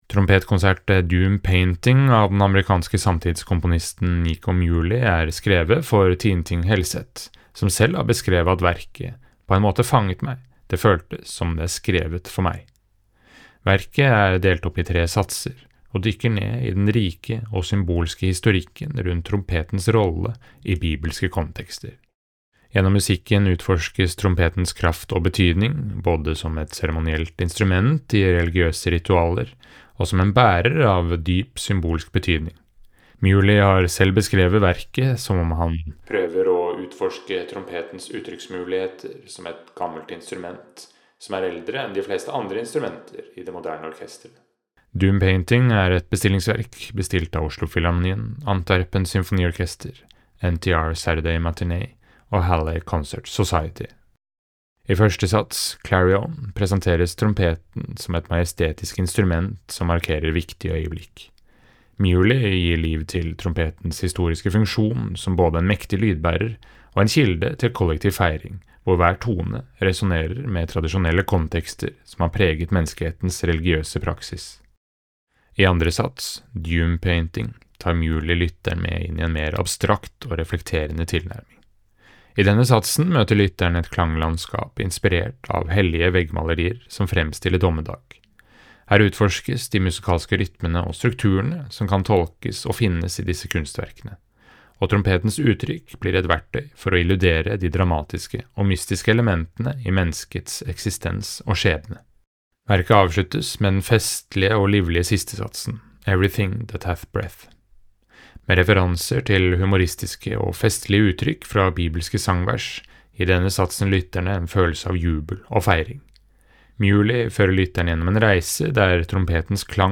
VERKOMTALE-Nico-Muhlys-Doom-Painting.mp3